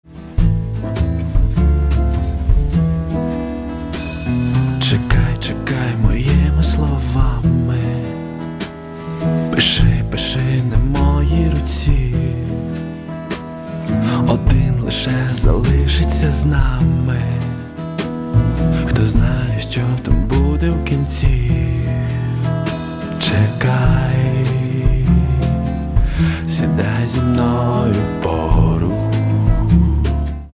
• Качество: 320, Stereo
гитара
мужской вокал
спокойные
медленные
блюз